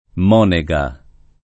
Monega [ m 0 ne g a ]